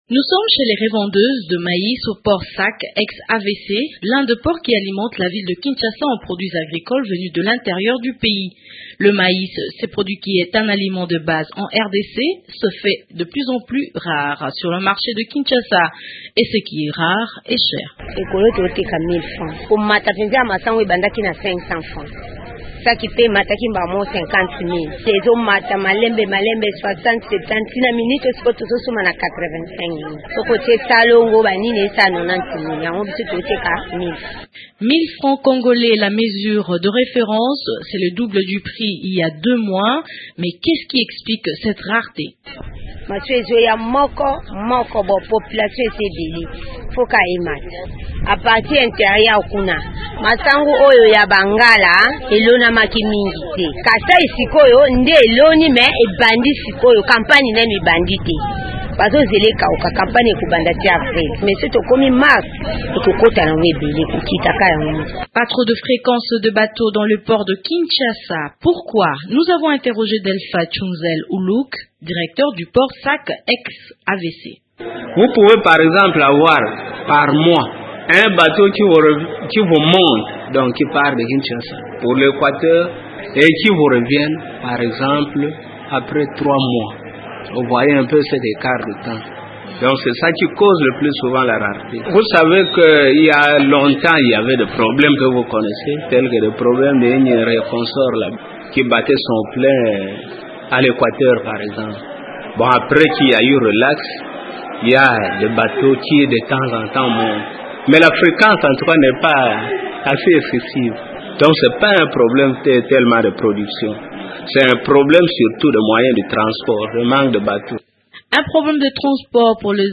Radio Okapi est partie à la rencontre des vendeuses de maïs et des opérateurs économiques de ce secteur.